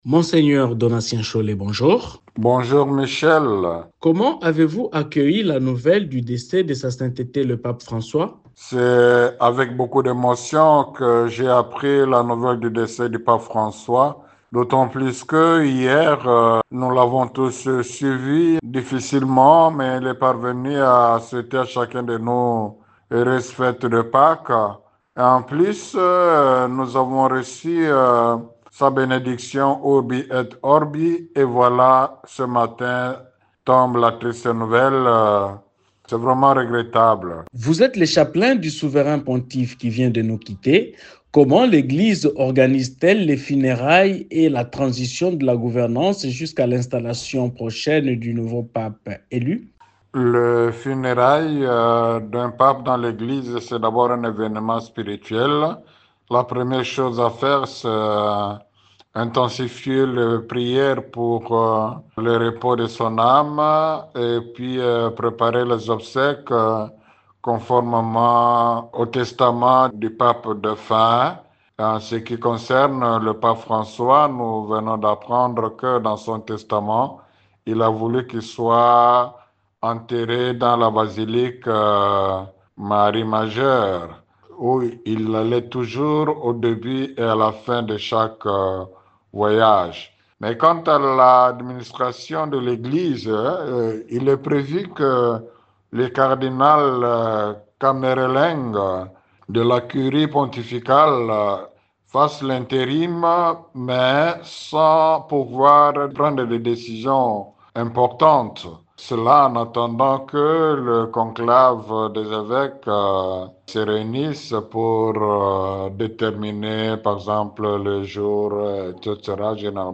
Au cours de cet entretien, il a également abordé l’impact du décès du pape, l’organisation des funérailles et la procédure de succession.